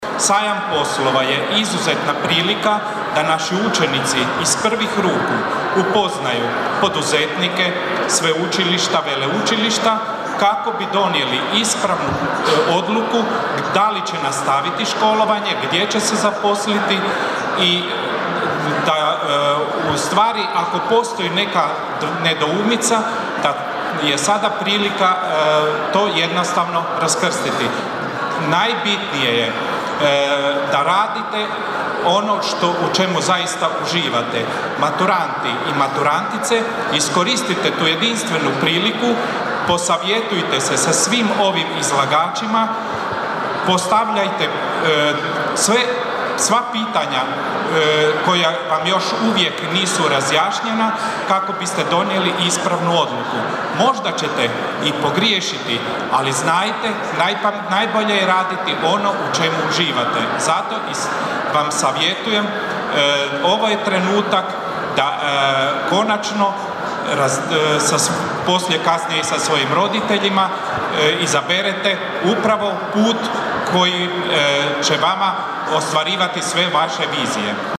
U maloj dvorani Sportskog centra „Franko Mileta“ u Labinu održan je danas Sajam poslova Labin 2026, višegodišnji projekt Grada Labina i Srednje škole Mate Blažine, koji je još jednom potvrdio svoju važnu ulogu u povezivanju mladih s tržištem rada i obrazovnim mogućnostima.